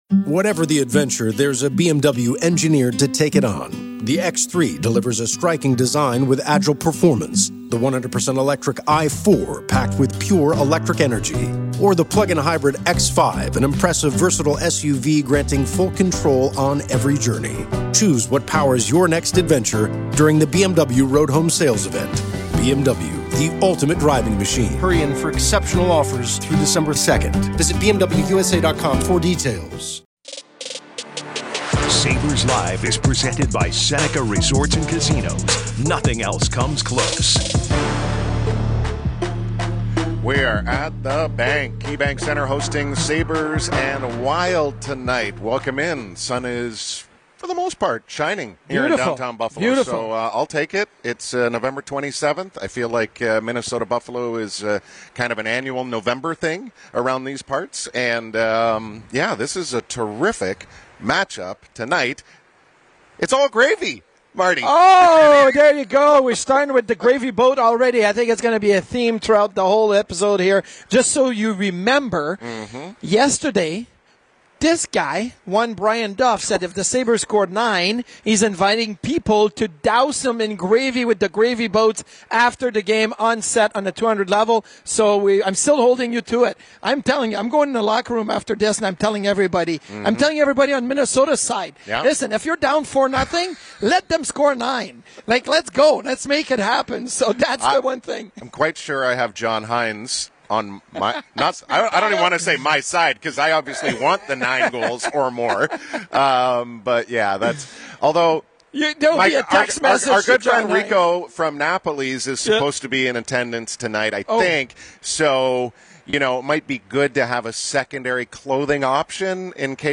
No radio station in the USA talks more hockey than WGR Sports Radio 550 and no radio station talks more Buffalo Sabres and NHL hockey better than us. Heard daily from 12PM-1PM on WGR and simulcast on MSG TV, Sabres Live goes deep into the corners everyday, breaking down the play and the players of every Sabres game.